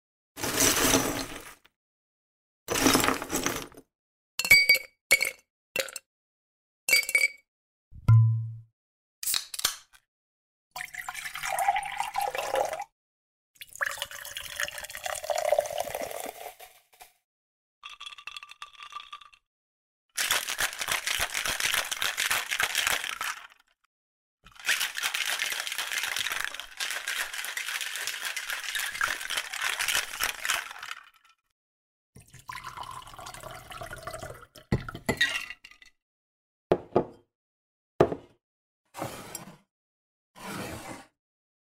Âm thanh Pha chế, Bartending, Cocktail mixing, Shaker…
Tiếng Lắc bình lắc Cocktail… pha chế đồ uống Tiếng Bình lắc Cocktail Shaker Bartender pha chế đồ uống
Thể loại: Tiếng ăn uống
Description: Âm thanh pha chế đồ uống tại quầy bar, bao gồm tiếng lắc bình shaker, tiếng rót rượu, tiếng đá viên va chạm vào ly, tiếng khuấy cocktail hay tiếng bật nắp chai, tái hiện không khí quán bar chuyên nghiệp và nghệ thuật cocktail mixing.
am-thanh-pha-che-bartending-cocktail-mixing-shaker-www_tiengdong_com.mp3